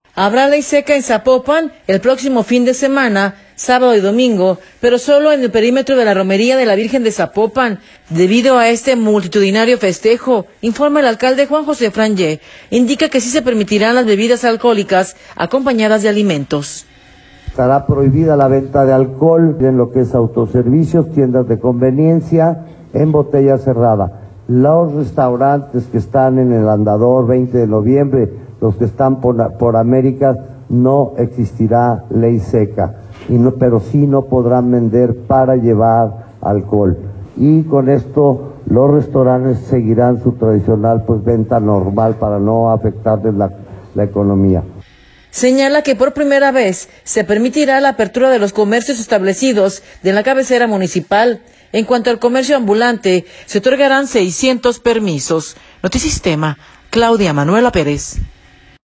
Habrá ley seca en Zapopan el próximo fin de semana, sábado y domingo, pero solo en el perímetro de la romería de la Virgen de Zapopan, debido a este multitudinario festejo informa el alcalde, Juan José Frangie. Indica que si se permitirán las bebidas alcohólicas acompañadas de alimentos.